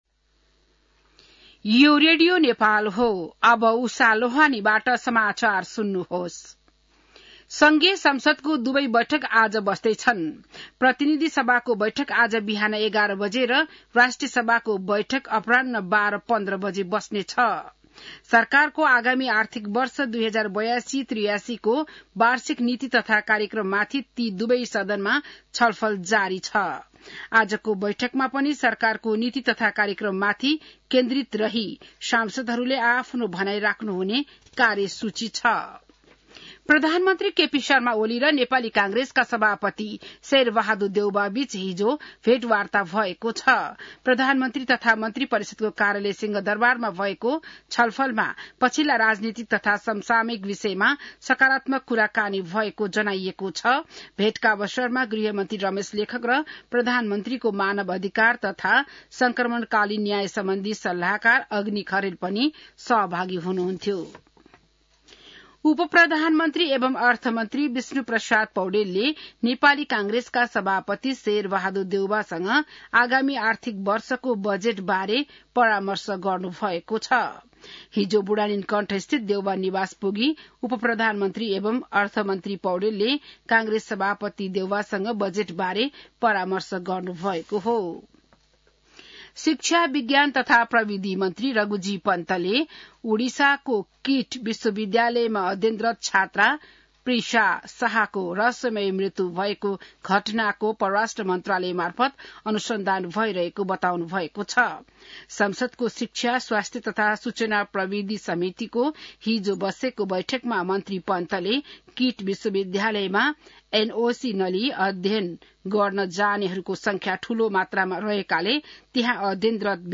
बिहान १० बजेको नेपाली समाचार : २३ वैशाख , २०८२